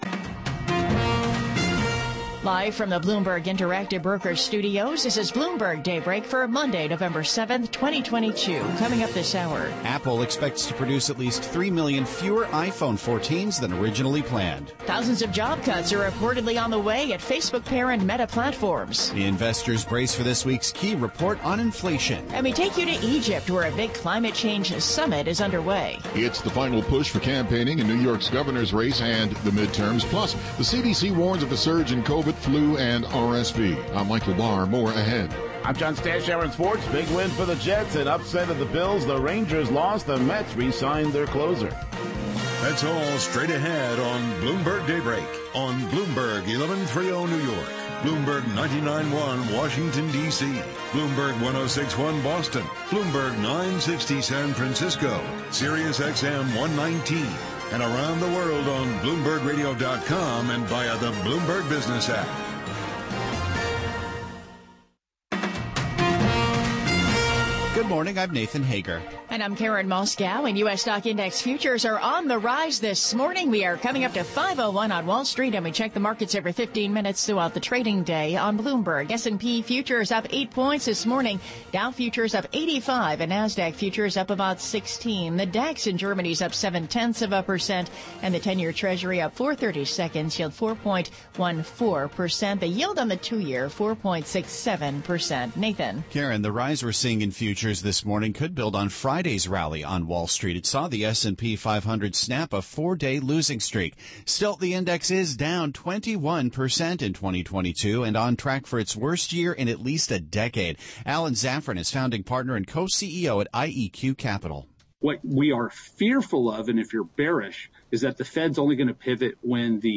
Bloomberg Daybreak: November 7, 2022 - Hour 1 (Radio)